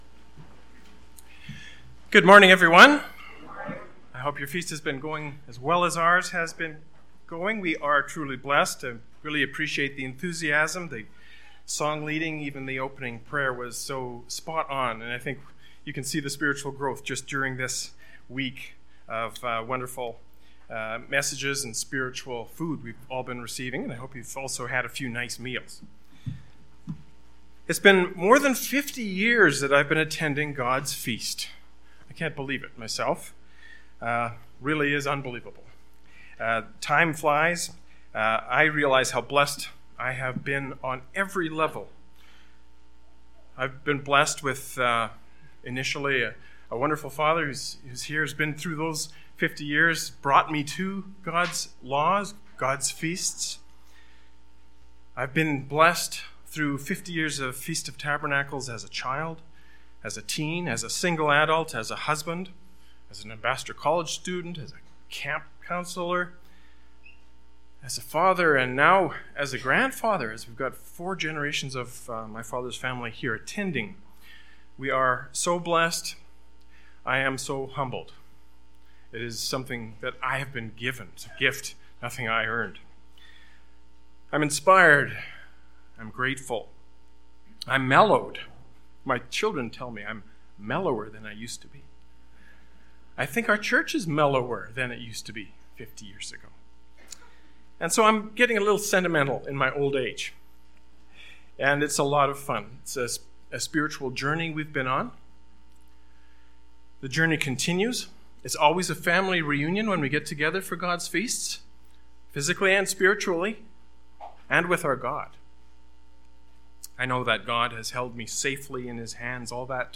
This sermon was given at the Canmore, Alberta 2016 Feast site.